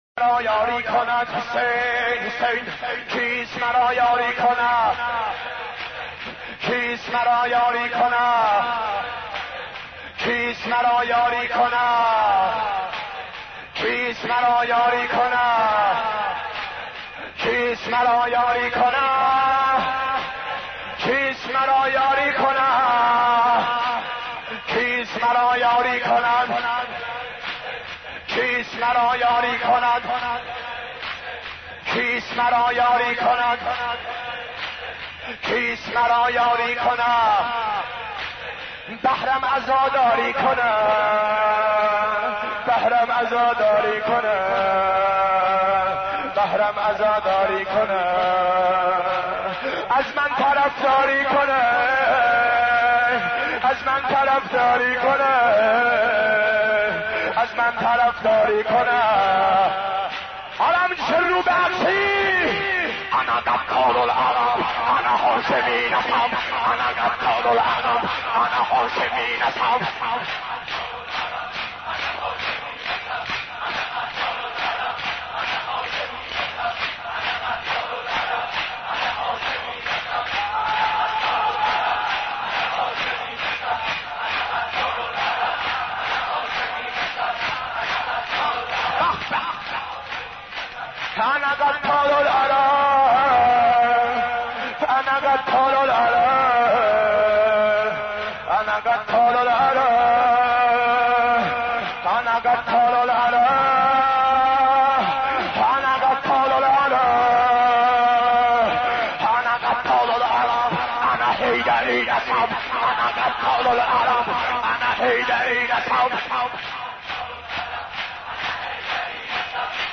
حضرت عباس ع ـ شور 20